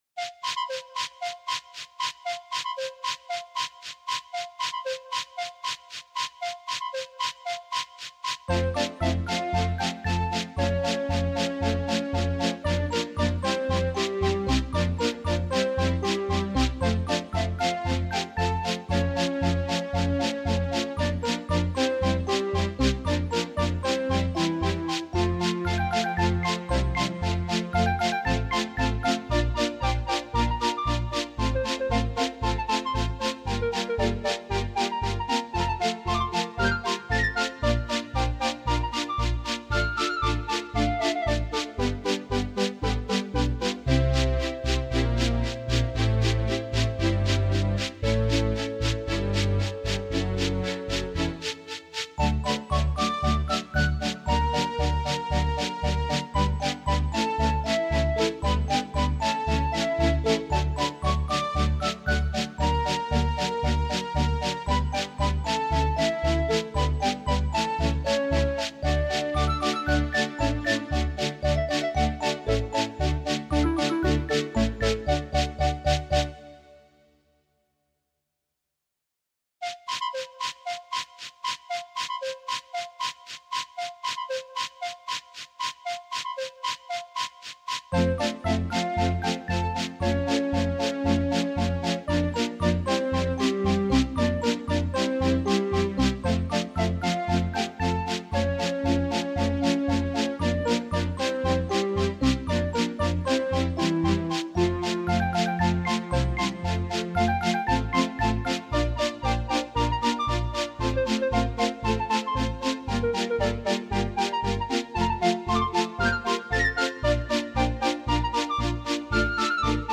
LEGO Railway Layout by LNUR Northern was the most unique thing I saw at the National Festival of Railway Modelling!